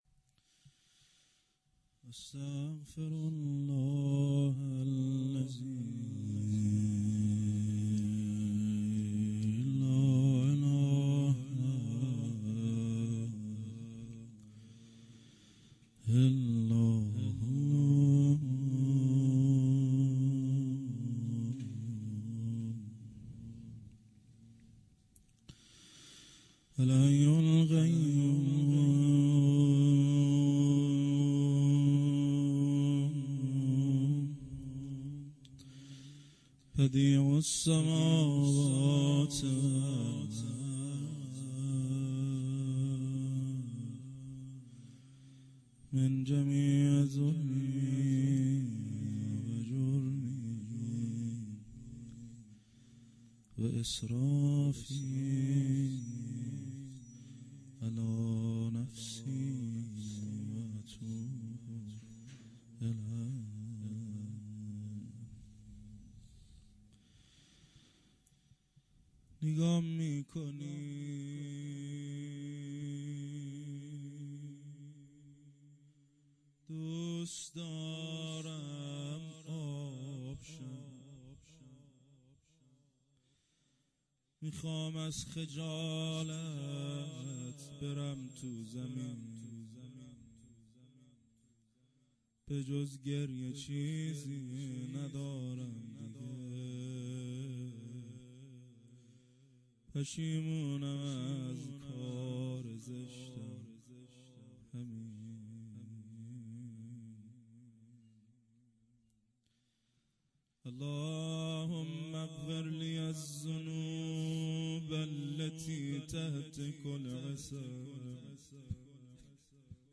روضه هفتگی(گرامیداشت حماسه 9 دی)--جمعه 7 دیماه 97